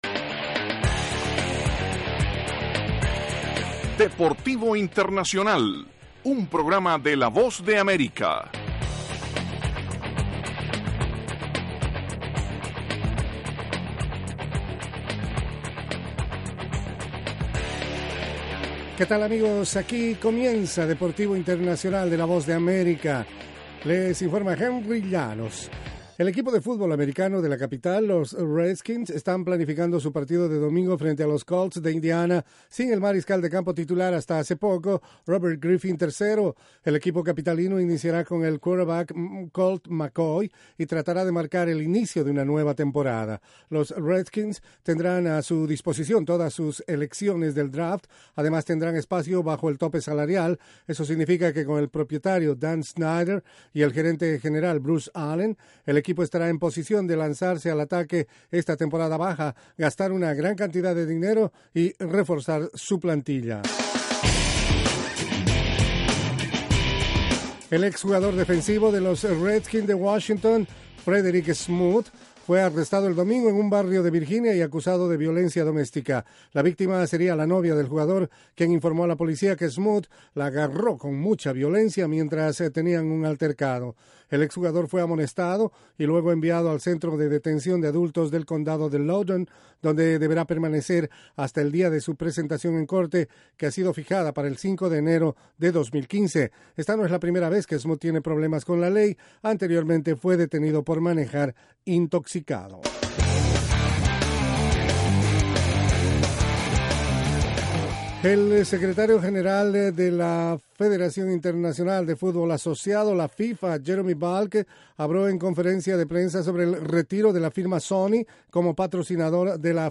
presenta las noticias más relevantes del mundo deportivo desde los estudios de la Voz de América.